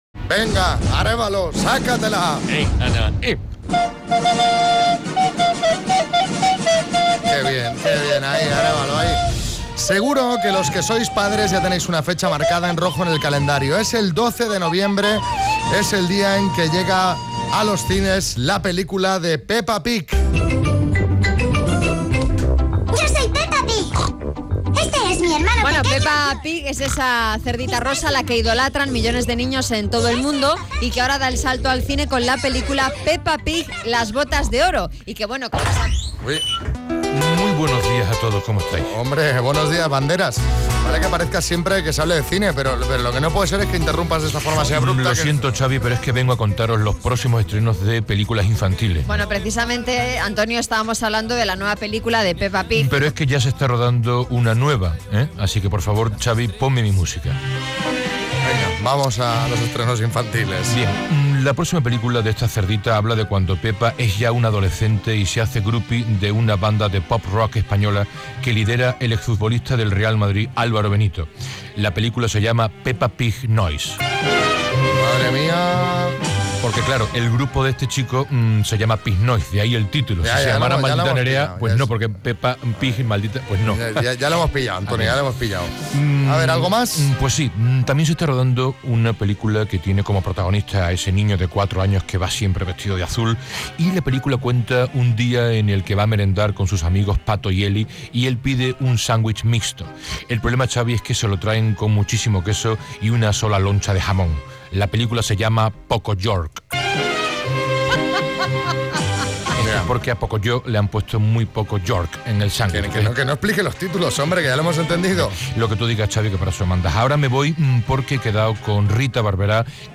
El 12 de noviembre se estrena en los cines la película de Peppa Pig: las botas de oro. Ha sido oírnos hablar de cine y Antonio Banderas se ha colado en el estudio para adelantarnos otros estrenos que llegarán pronto a las salas.